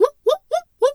pgs/Assets/Audio/Animal_Impersonations/zebra_whinny_04.wav at master
zebra_whinny_04.wav